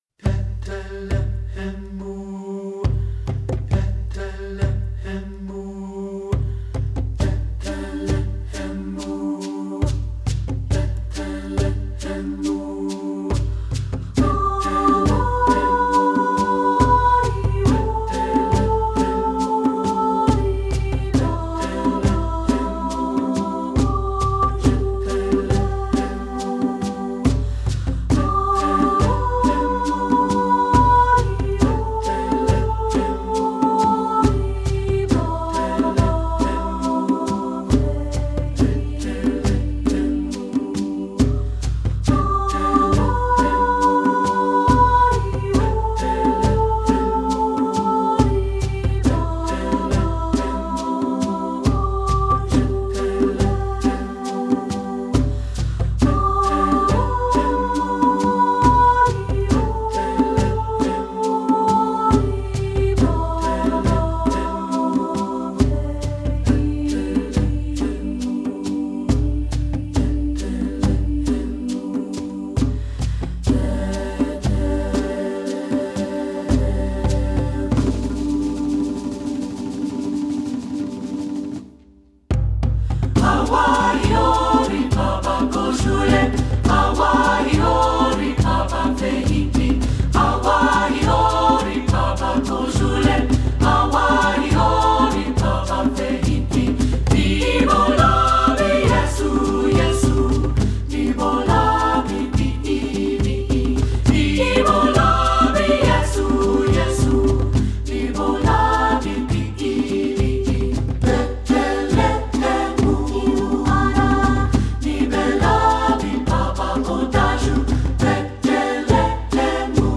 Composer: Nigerian Christmas C
Voicing: 2-Part